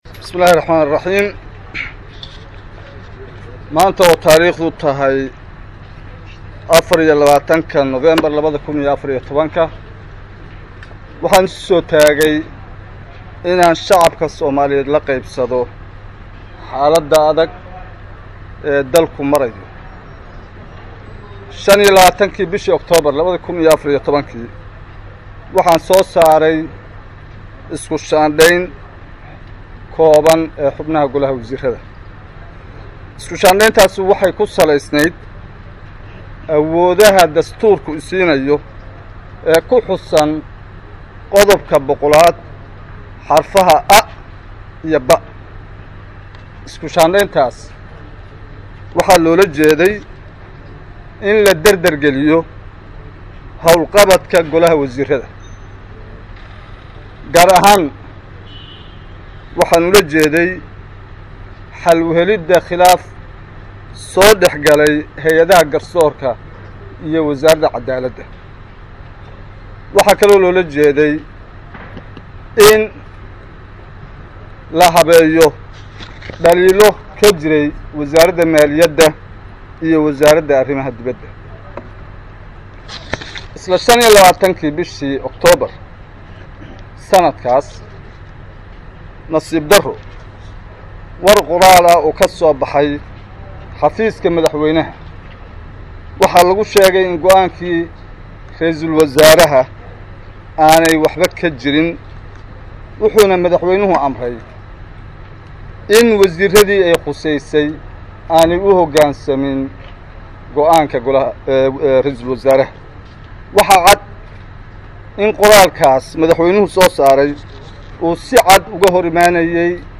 Isniin, November 24, 2014 (HOL) — Ra’iisul wasaaraha Somalia C/weli Shiikh Axmed oo shir jaraa’id caawa ku qabtay Muqdisho ayaa sheegay inuu diyaar u yahay in lagu xalliyo khilaafka taagan hab wadahadal ah, isagoo mooshinka baarlamaanka laga geeyayna ku til-mamaay mid sharci-darro ah oo carqalad ku noqday geeddi socodka Hiigsiga 2016-ka, isla markaana lagu difaacayo wasiir saaxiib la ah madaxweynaha.
DHAGEYSO: Shirka Jaraa'id ee Ra'iisul Wasaaraha Somalia
DHAGEYSO_Shirka_Jaraa'id_ee_RW_Somalia_HOL.mp2